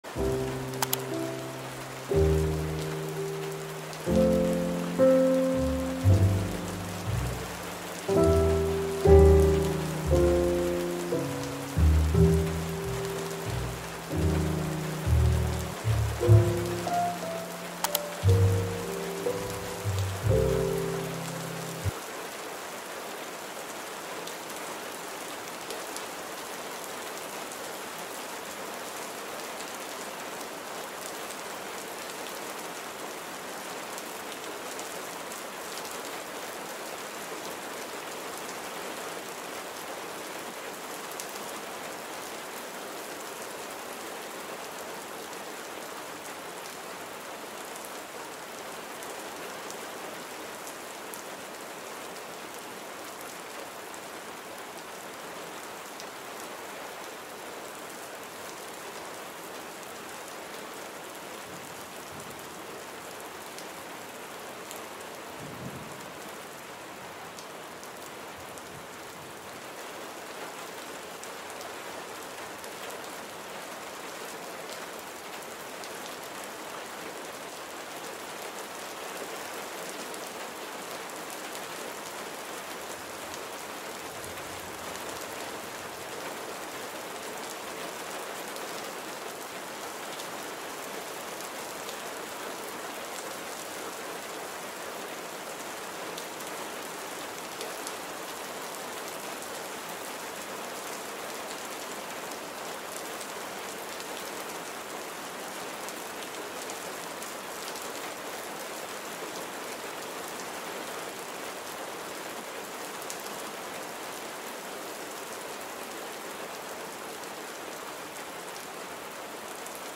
Estudio con lluvia en cafetería real durante 1 hora